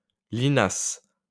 Linas (French pronunciation: [linas]
or [lina]) is a commune in the Essonne department in Île-de-France in northern France.